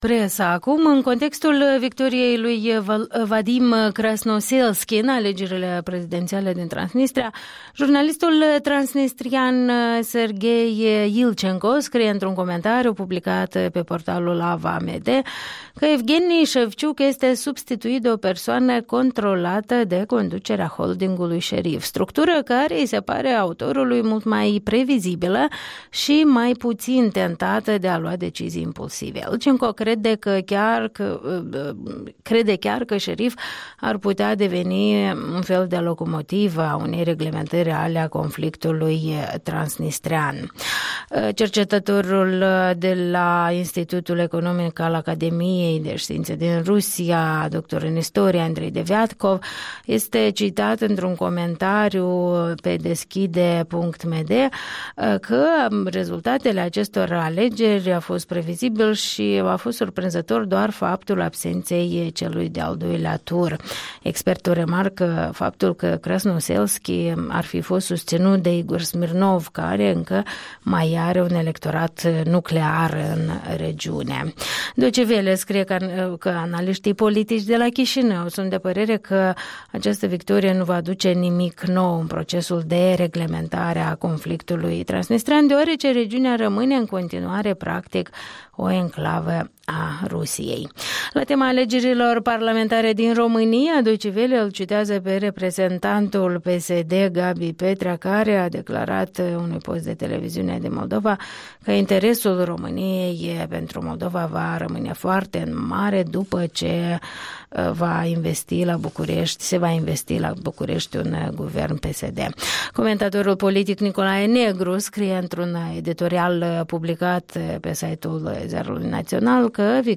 Revista presei